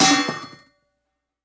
Swing Efeito Sonoro: Soundboard Botão